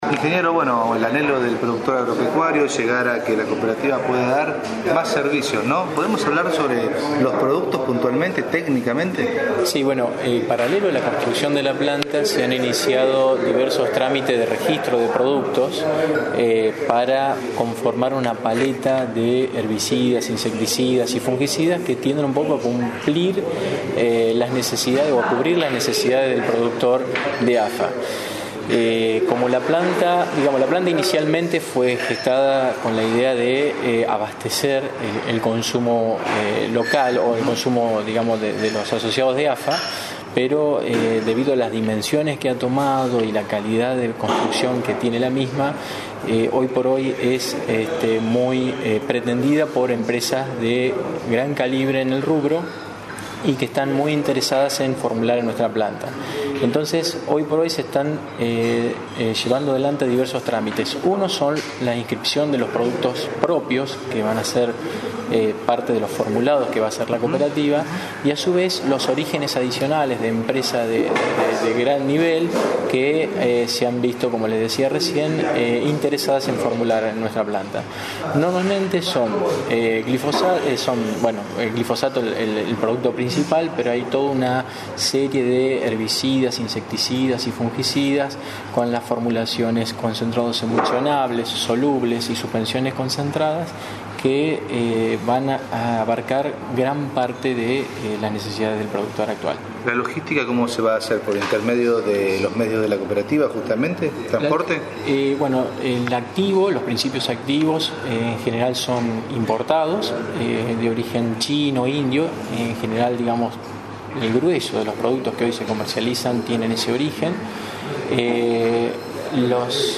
RUEDA DE PRENSA PREVIA CON PERIODISTAS INVITADOS.